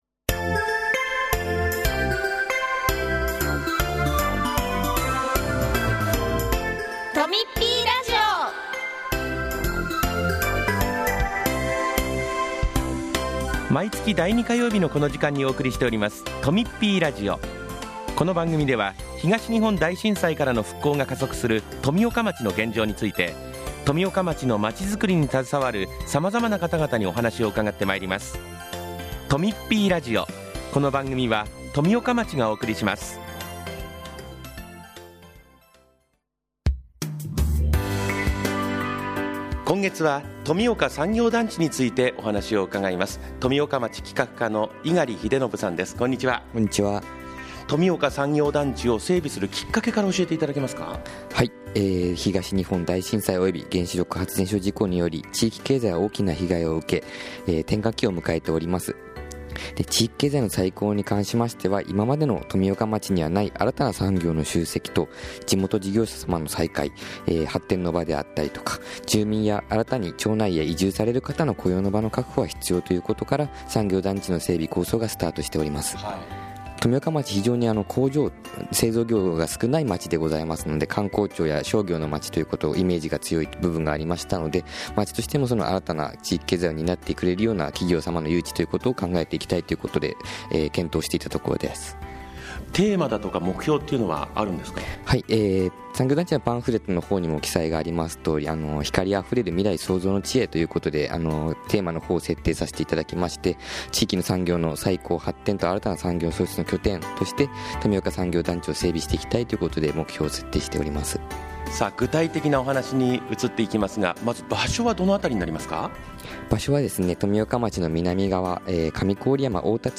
今回は、企画課の職員による「産業団地」のインタビューです。その他、町からのお知らせもあります。